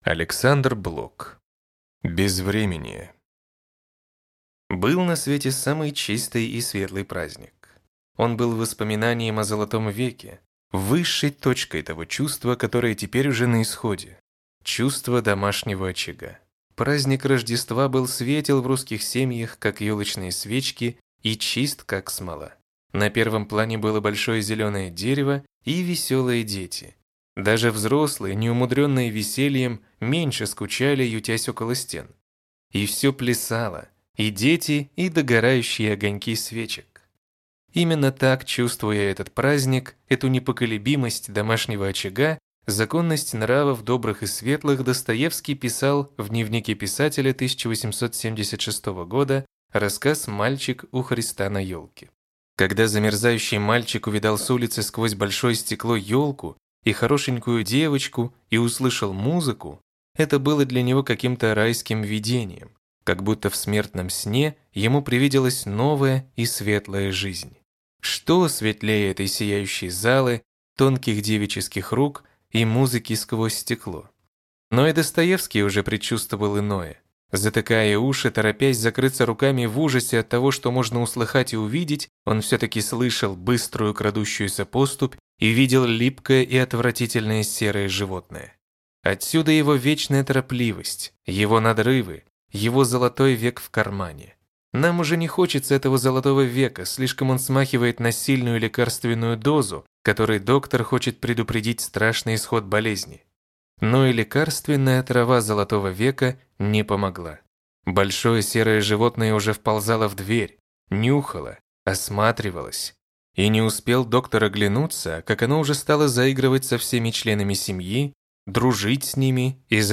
Аудиокнига Безвременье | Библиотека аудиокниг